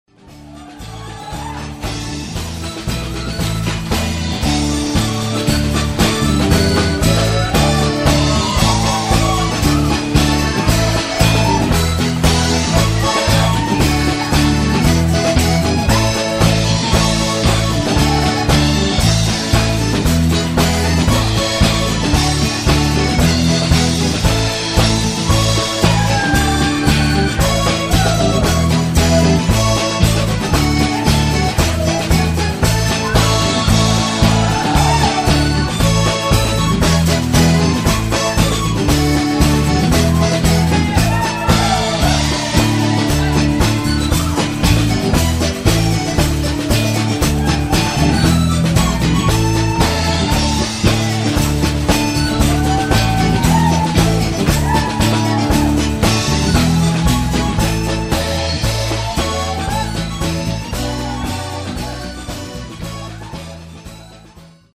These sound clips (below) were recorded at a live Scottish Ceilidh themed event:
Please note: the band's instrumentation for the ceilidh material is: Drums, bass, guitar, keys/piano and the maximum duration of the band's ceilidh set is 45 mins.
CeilidhDashingWhiteSergeant.mp3